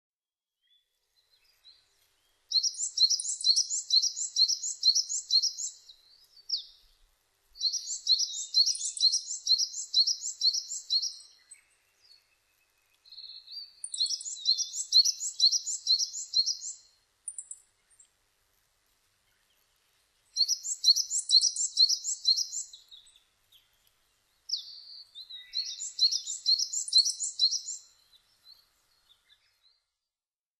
ヒガラ　Parus aterシジュウカラ科
日光市稲荷川中流　alt=730m  HiFi --------------
Mic.: Sound Professionals SP-TFB-2  Binaural Souce
他の自然音：　 ホオジロ・ウグイス・オオルリ